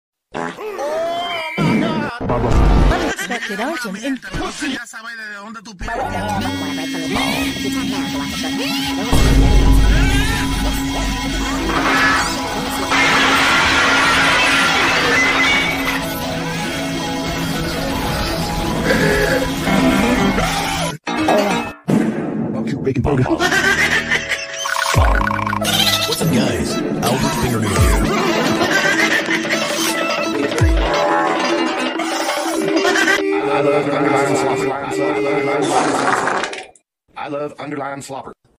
The Ultimate Random Goofiest Goofy sound effects free download
The Ultimate Random Goofiest Goofy ahh sounds Sound Effect
You Just Search Sound Effects And Download. funny sound effects on tiktok Download Sound Effect Home